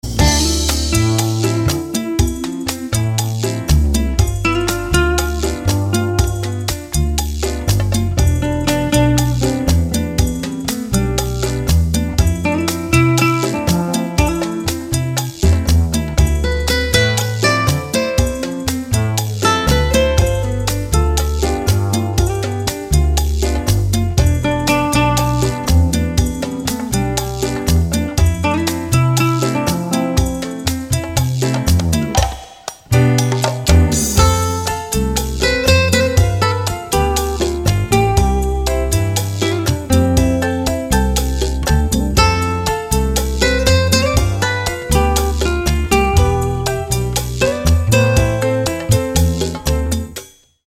• Качество: 192, Stereo
гитара
спокойные
без слов
инструментальные
пианино
романтичные
New Age